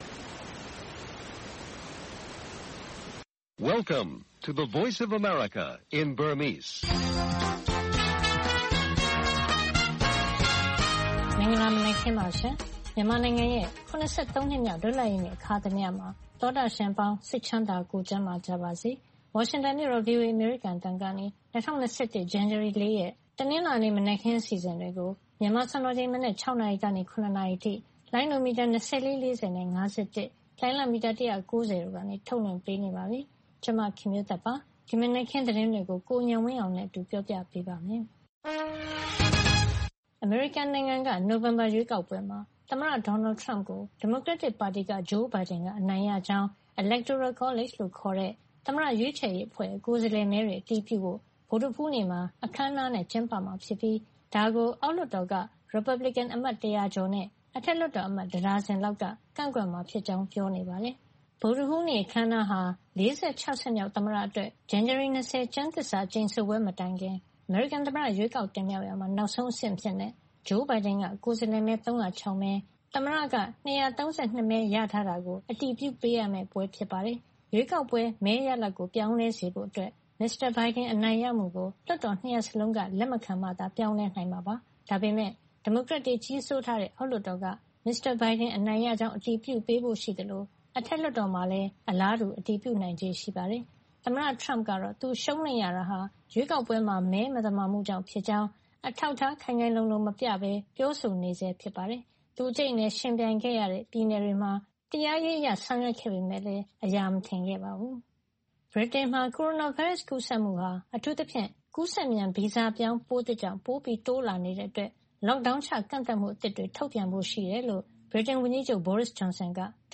အာဏာရ အမျိုးသား ဒီမိုကရေစီ အဖွဲ့ချုပ်နဲ့ မွန် ညီညွတ်ရေး ပါတီ ဆွေးနွေးမယ့် အခြေအနေ၊ ကုလသမဂ္ဂက မြန်မာ့ အရေး ဆုံးဖြတ်ချက် စတဲ့ ထိပ်တန်းရောက်သတင်းတွေ နားဆင်ရပါမယ်။ နောက်ပိုင်းမှာတော့ မြန်မာ့အရေး သုံးသပ်ချက်၊ ပြည်တွင်း သတင်းသုံးသပ်ချက်၊ ၂၀၂၀ ပြည့်နှစ်ရဲ့ ပျော်ရွှင်ဖွယ် သတင်းများ အထူးအစီအစဉ်၊ ထူးခြား ဆန်းပြား အပတ်စဉ် အစီအစဉ်တွေလည်း နားဆင်ရပါမယ်။ ၂၀၂၁ ခုနှစ် ဇန်နဝါရီလ ( ၄ ) ရက် တနလာၤနေ့ နံနက် ၆း၀၀ နာရီက ၇:၀၀ နာရီ ရေဒီယိုအစီအစဉ်။